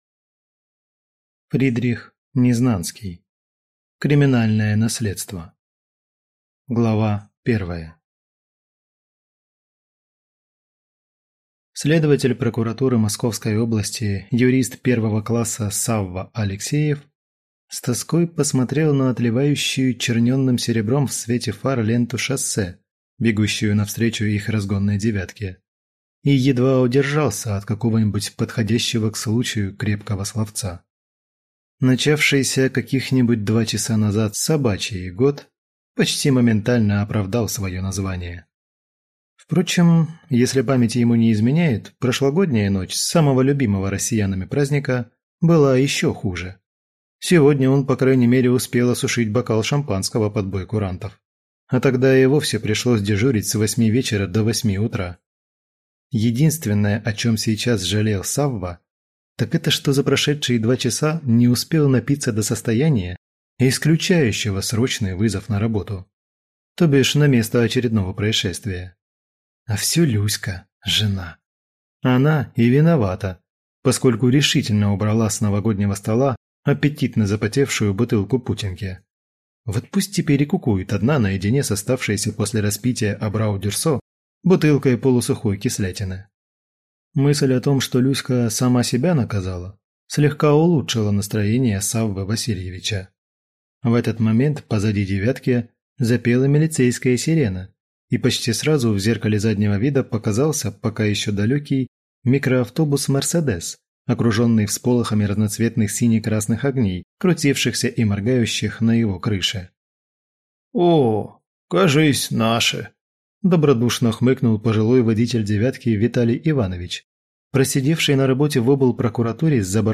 Аудиокнига Криминальное наследство | Библиотека аудиокниг